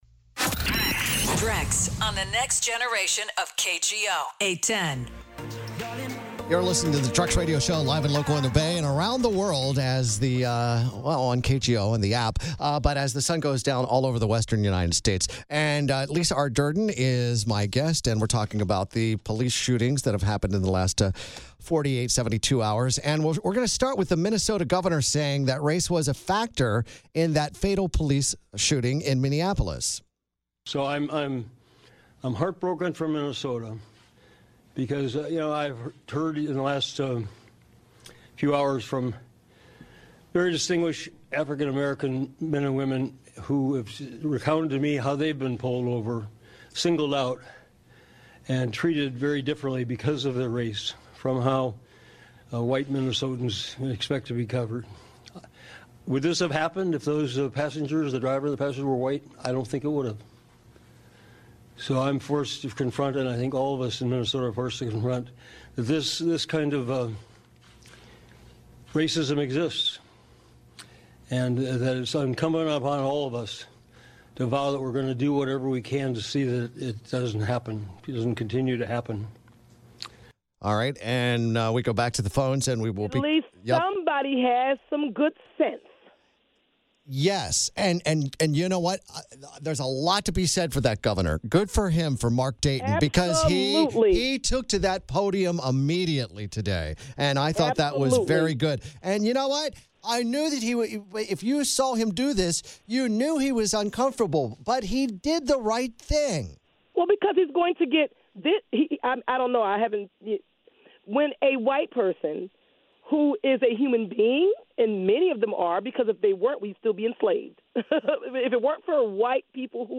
Hear the Minnesotta Gov.'s response to the police shooting of Philando Castile, calling it an act of racism. Callers comment on criminal compliance vs assassination, on the nature of being an under represented minority, and on police conduct.